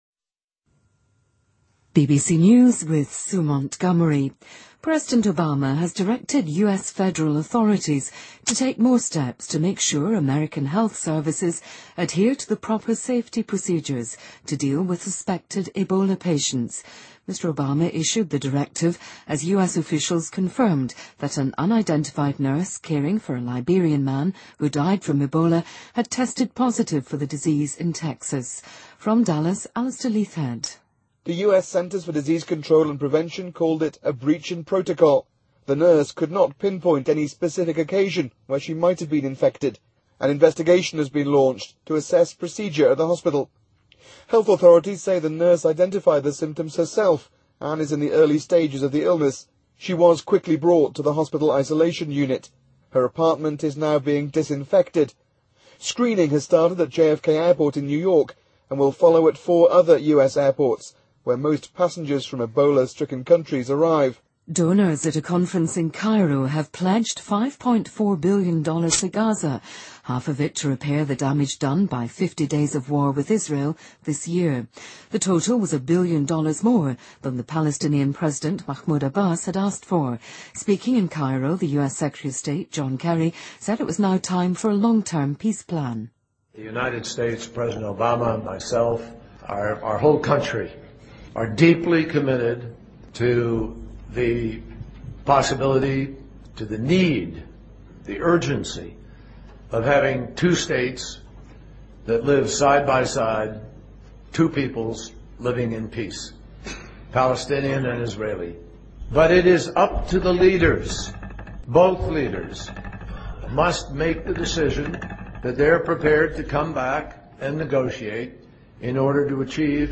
您的位置：BBC > BBC在线收听 > 10月新闻 > 最新BBC新闻